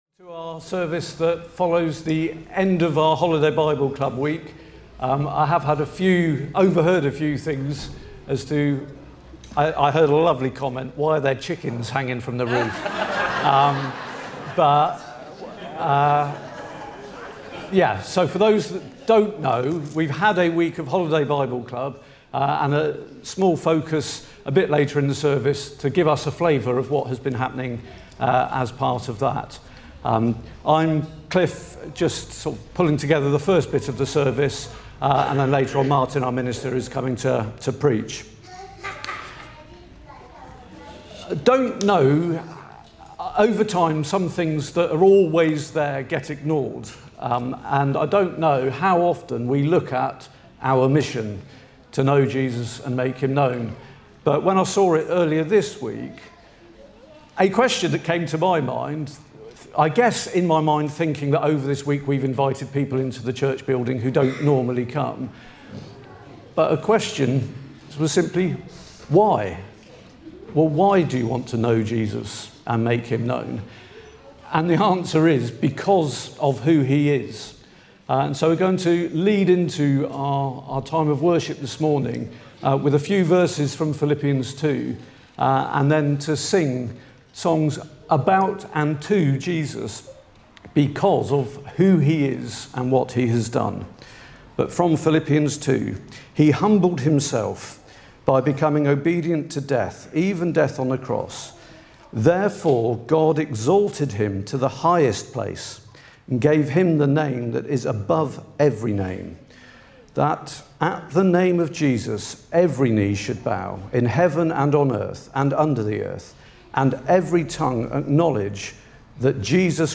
3 August 2025 – Morning Service